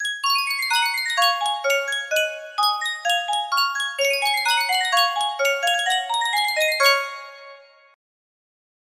Sankyo Miniature Spieluhr - MOFIHM YRQ music box melody
Full range 60